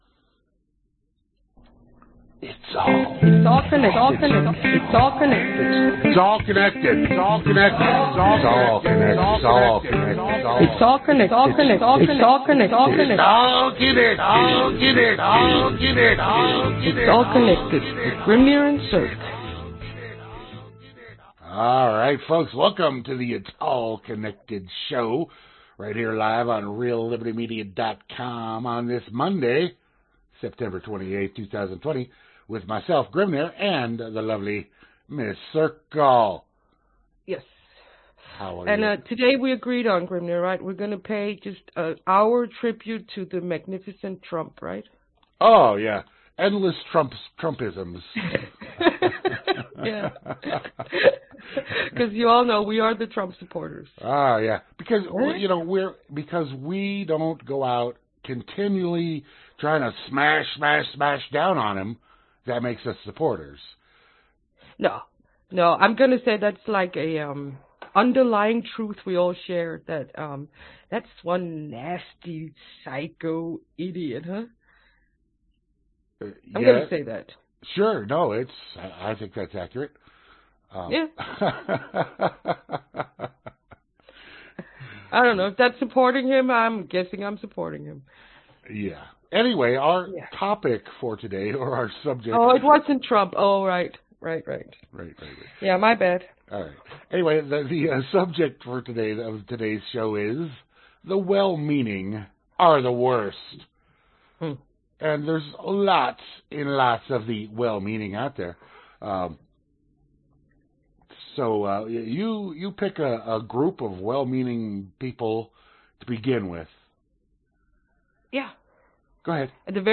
Genre Talk